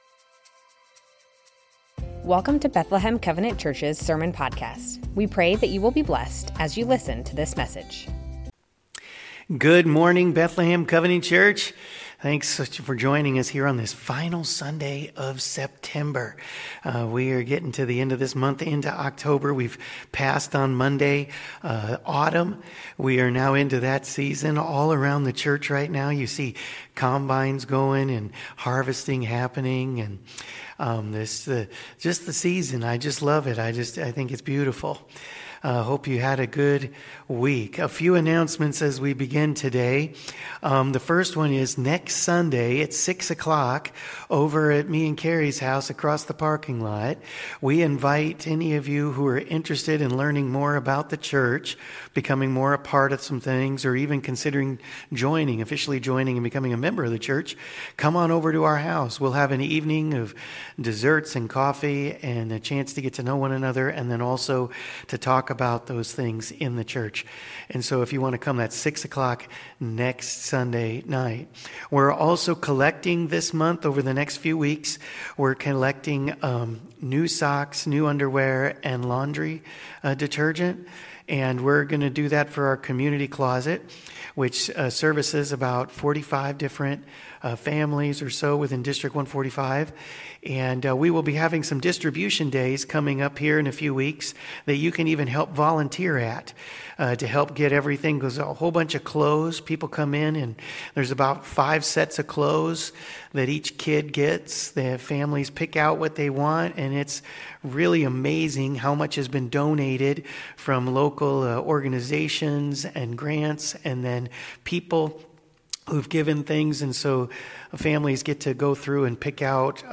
Bethlehem Covenant Church Sermons The Names of God - Jehovah Rapha Sep 28 2025 | 00:40:39 1x 00:00 / 00:40:39 Subscribe Share Spotify RSS Feed Share Link Embed